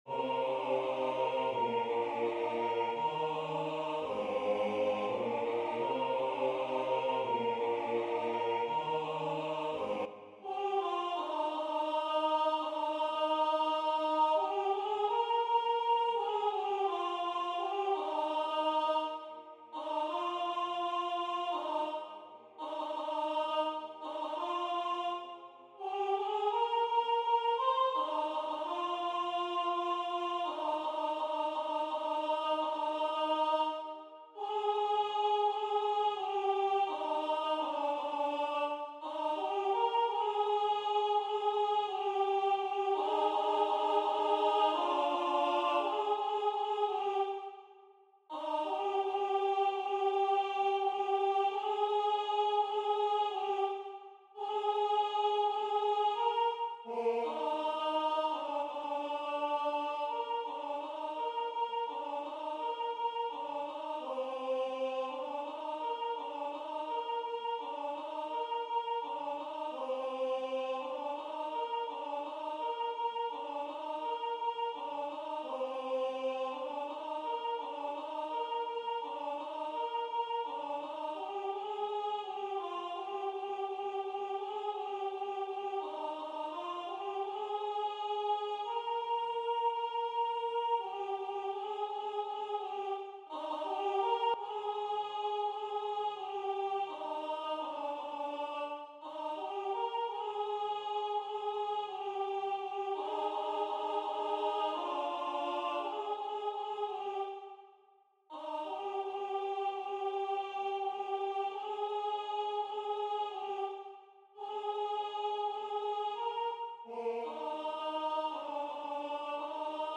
alt - computerstem